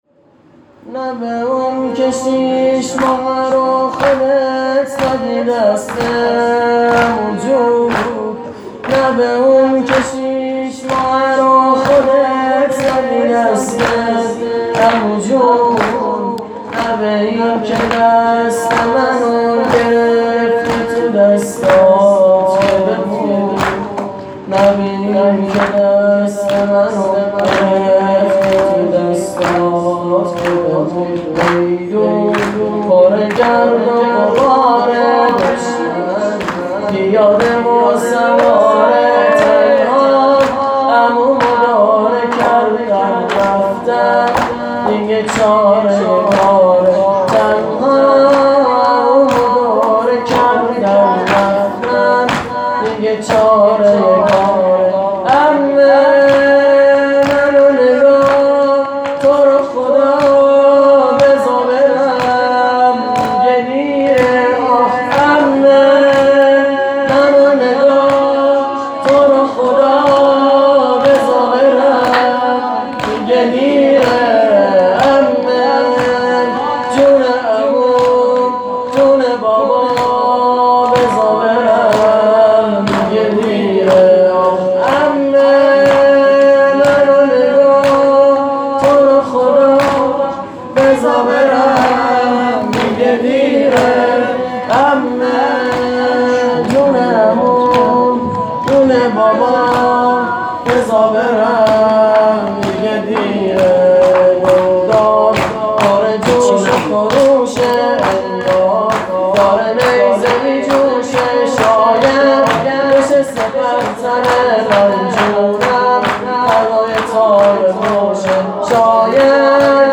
خیمه گاه - شجره طیبه صالحین - میدون پره گرده و غباره _ زمینه